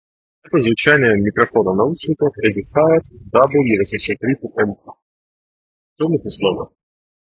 Edifier W830NB — Микрофон:
Не ужасно, однако в шумных условиях микрофон может отрезать половину слова.
В шумных условиях:
edifier-w830nb-shum.m4a